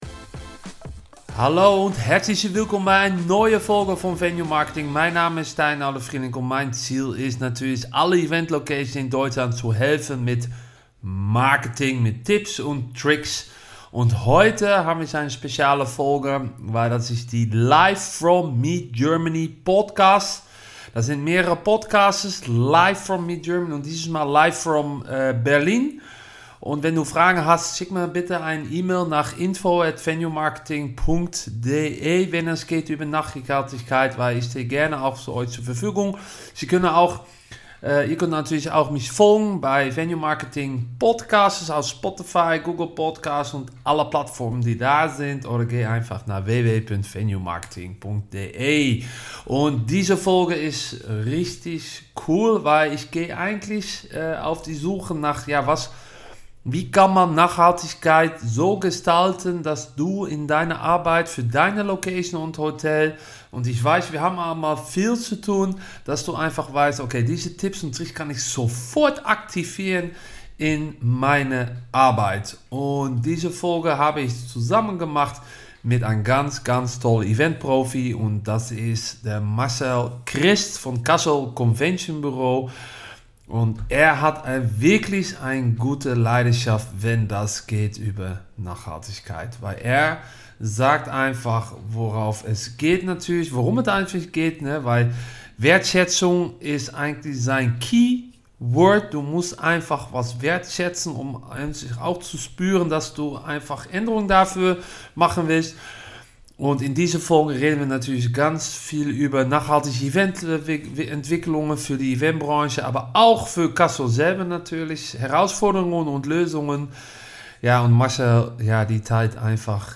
Live from Meet Germany Berlin ~ B2B Marketingpodcast für Eventlocations & Hotels